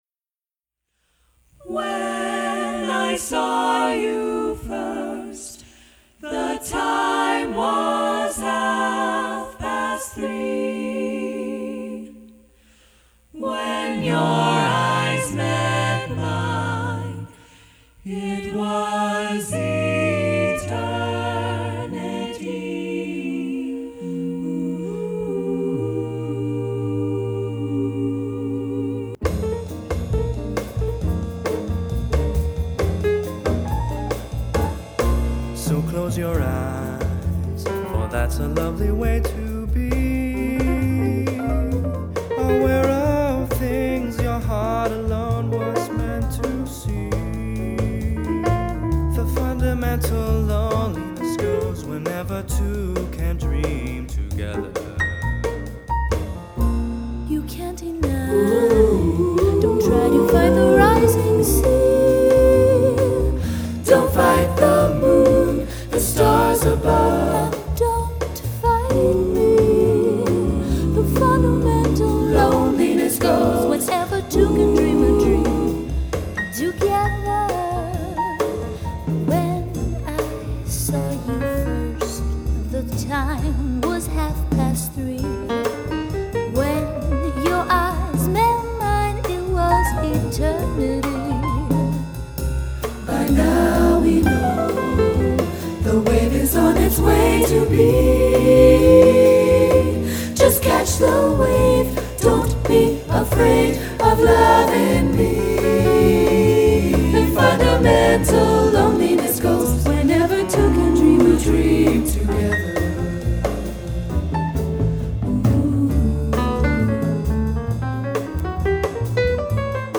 S A T B /rhythm section